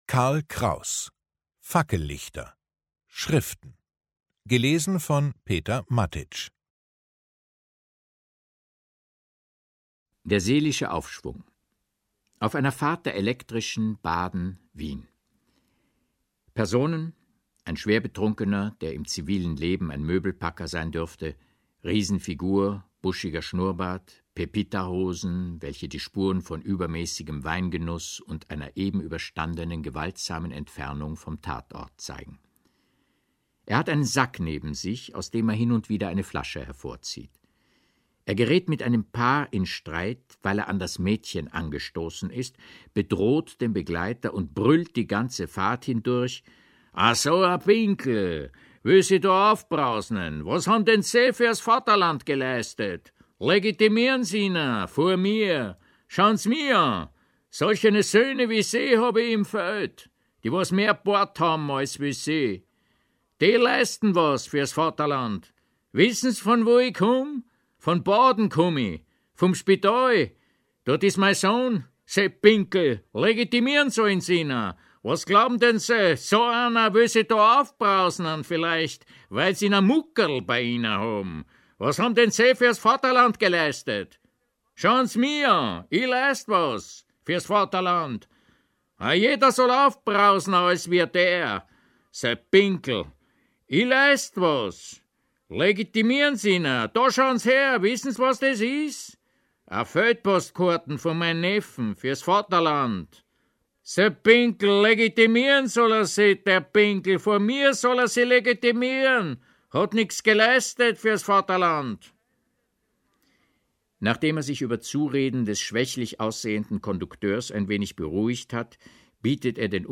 Peter Matić (Sprecher)
Peter Matić liest aus den Schriften dieses streitbaren Sprachgenies.